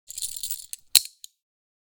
Prisoner, Handcuffs, Latch Opening, Lever Release Click 2 Sound Effect Download | Gfx Sounds
Prisoner-handcuffs-latch-opening-lever-release-click-2.mp3